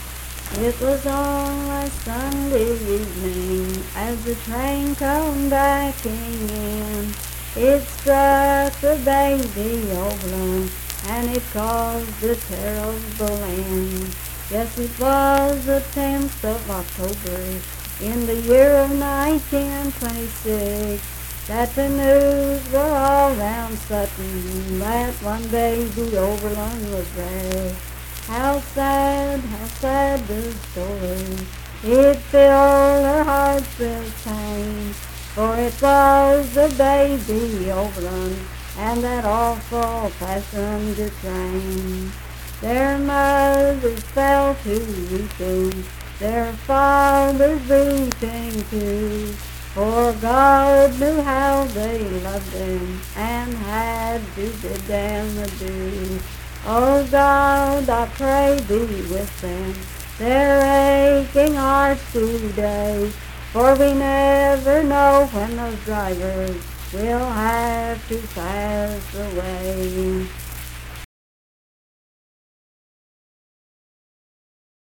Unaccompanied vocal music
Voice (sung)
Sutton (W. Va.), Braxton County (W. Va.)